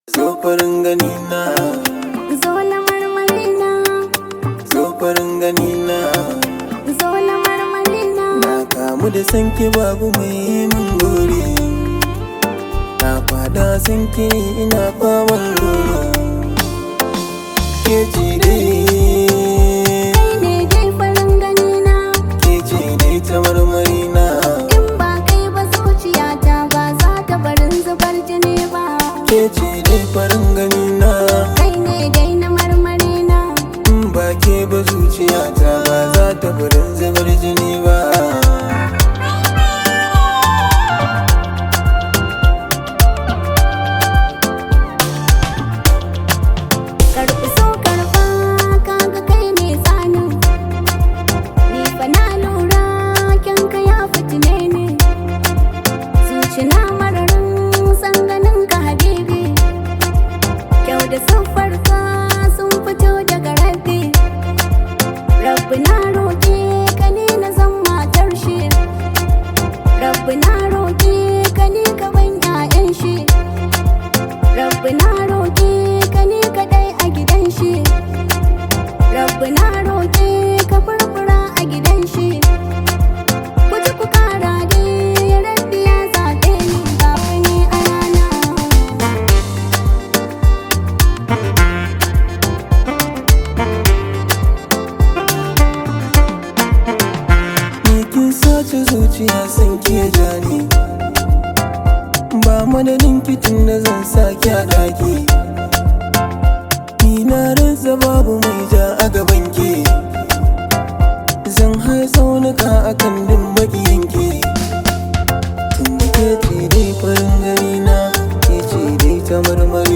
hausa music track
an Arewa rooted song